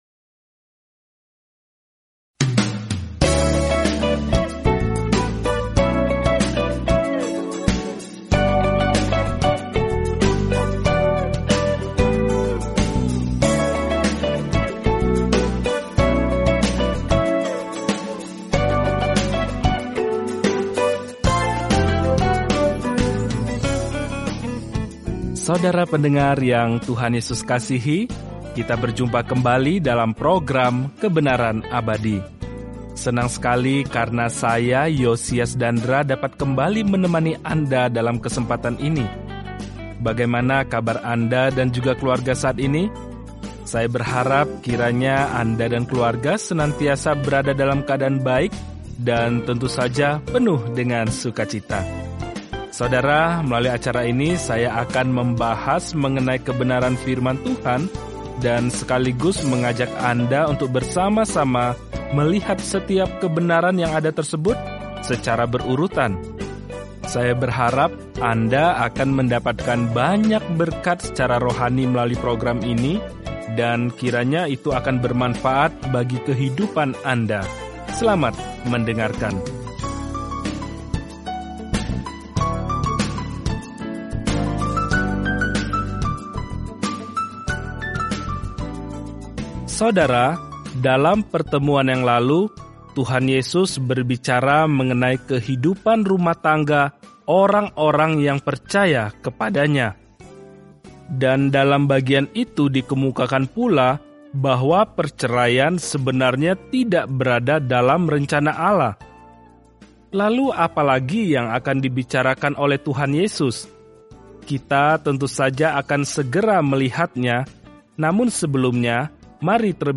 Firman Tuhan, Alkitab Matius 19:14-30 Matius 20:1-23 Hari 27 Mulai Rencana ini Hari 29 Tentang Rencana ini Matius membuktikan kepada para pembaca Yahudi kabar baik bahwa Yesus adalah Mesias mereka dengan menunjukkan bagaimana kehidupan dan pelayanan-Nya menggenapi nubuatan Perjanjian Lama. Telusuri Matius setiap hari sambil mendengarkan studi audio dan membaca ayat-ayat tertentu dari firman Tuhan.